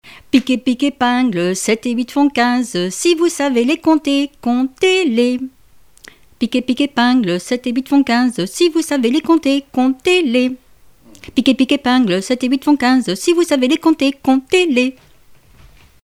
enfantine : comptine
Répertoire de chansons populaires et traditionnelles
Pièce musicale inédite